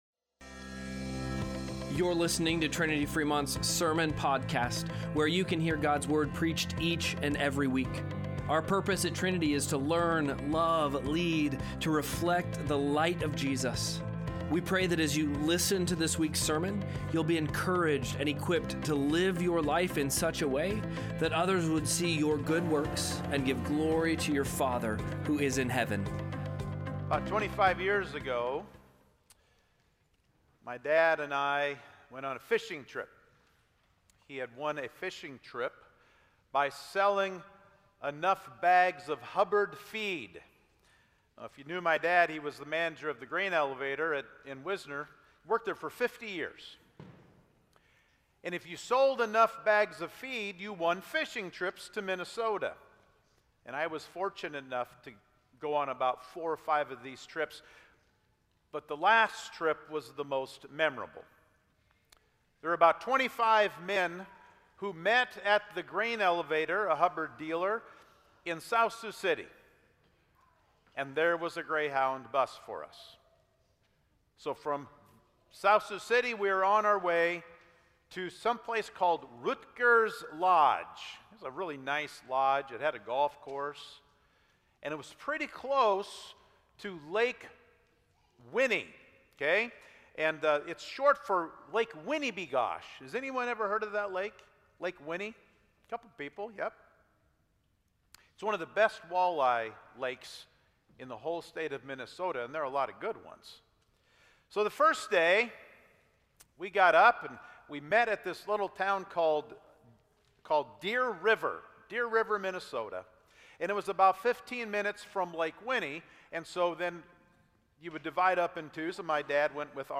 Sermon-Podcast-3-15.mp3